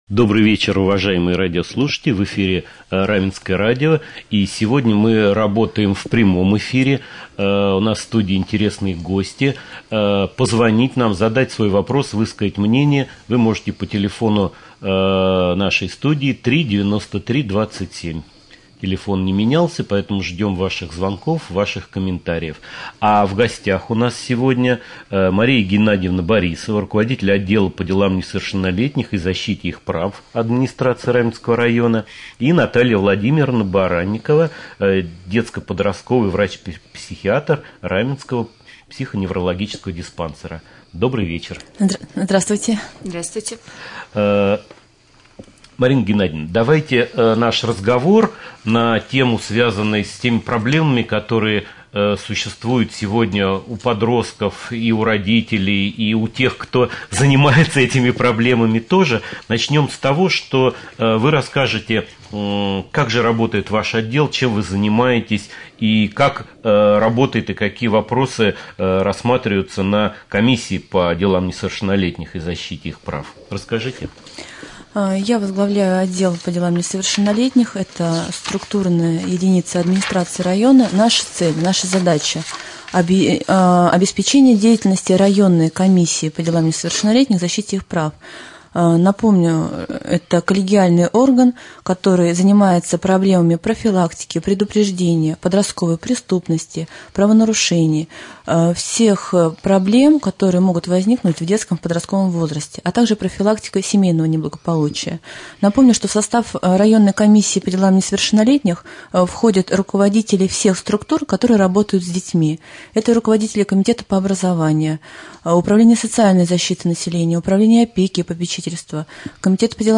Прямой эфир.
1.Прямой-эфир.mp3